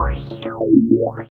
50 MD PERC-L.wav